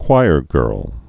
(kwīrgûrl)